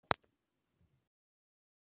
Tags: 808 drum cat kick kicks hip-hop